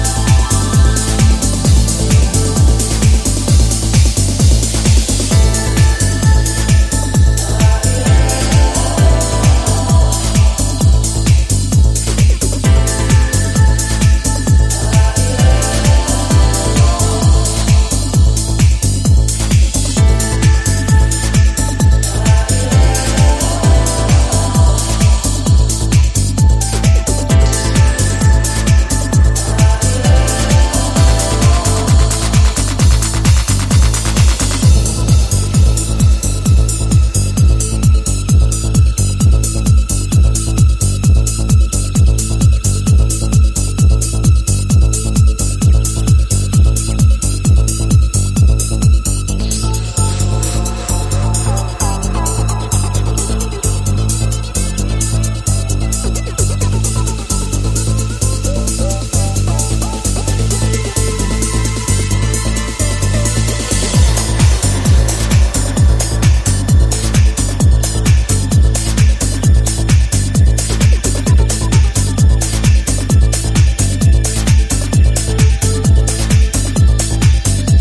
Summery, sensual & cunty
balearia-drenched remix